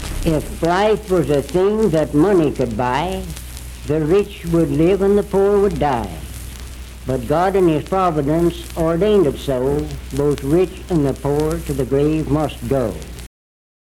Unaccompanied vocal music
Verse-refrain 1(4). Performed in Sandyville, Jackson County, WV.
Folklore--Non Musical, Bawdy Songs
Voice (sung)